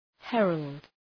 Shkrimi fonetik {‘herəld}